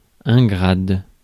Ääntäminen
IPA: /ɡʁad/